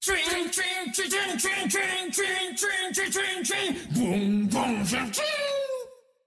(剣の音)